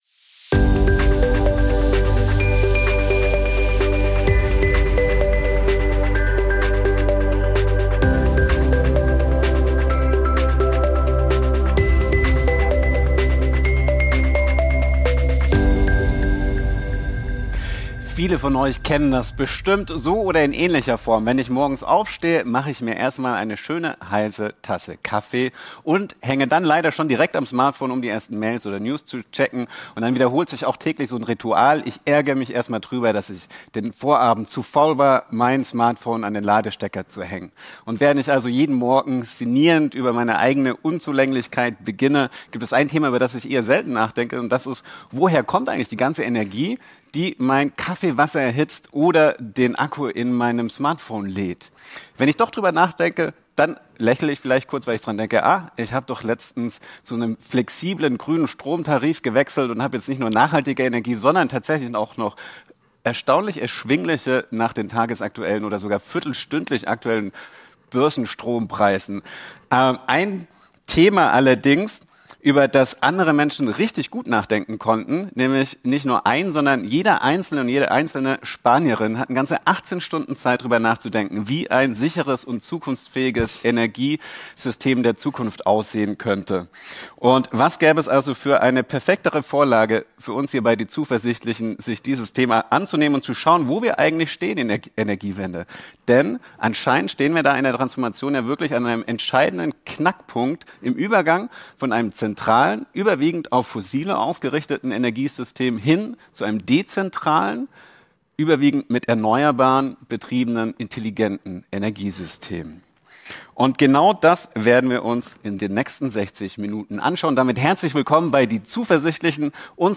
Es sprechen im nächsten Talk bei den Zuversichtlichen